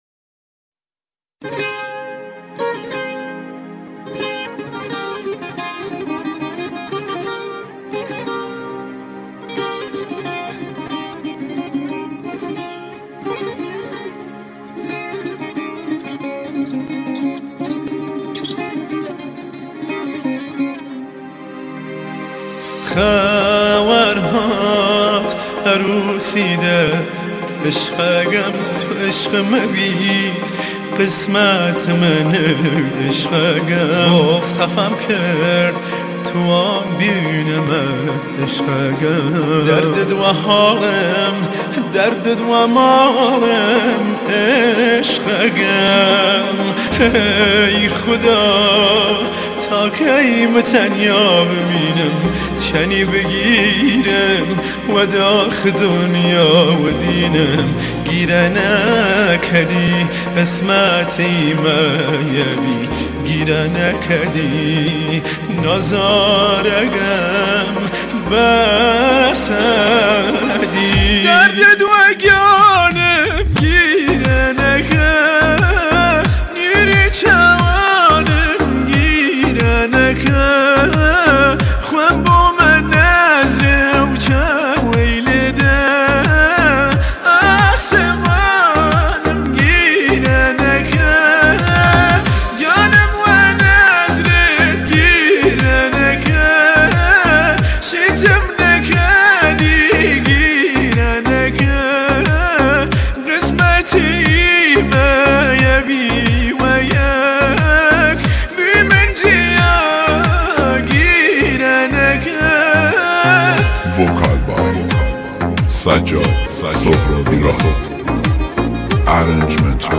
کردی احساسی و غمگین